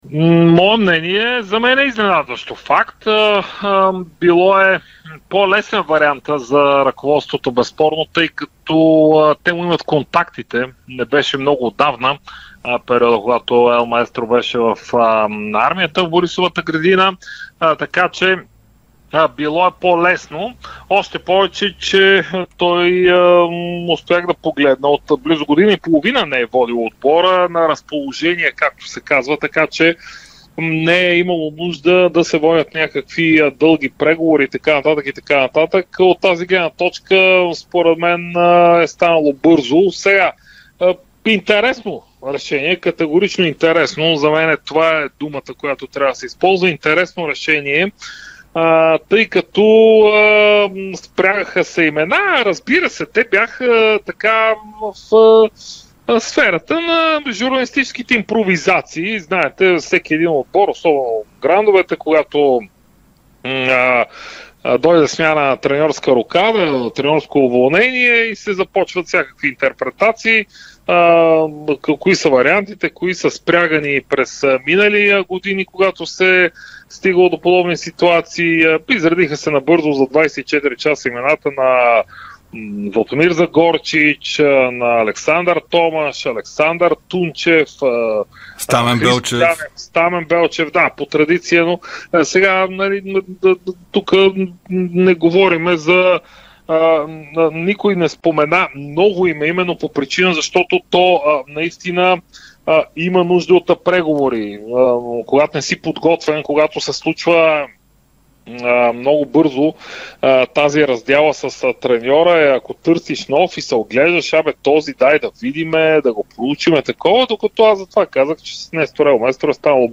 в разширеното издание на Спортното шоу в ефира на Дарик радио